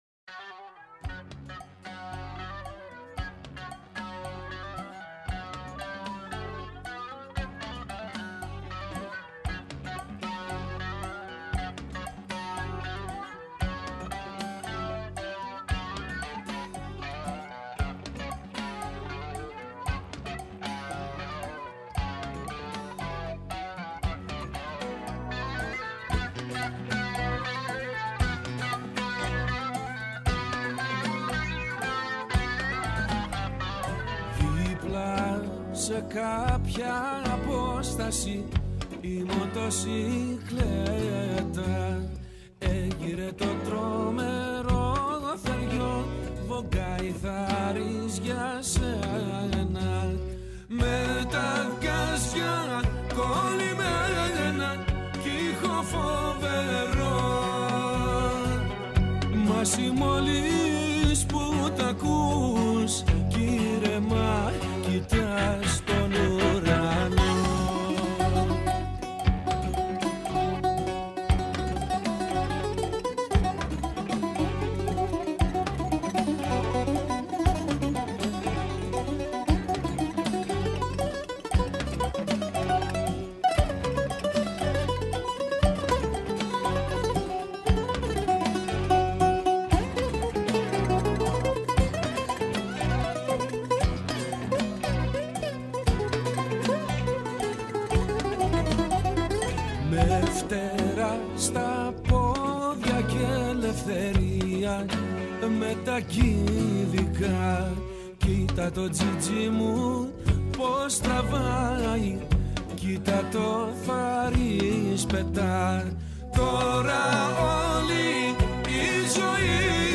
μιλά καλεσμένος στο studio του 9,58